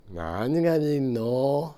Aizu Dialect Database
Type: Yes/no question
Final intonation: Falling
Location: Showamura/昭和村
Sex: Male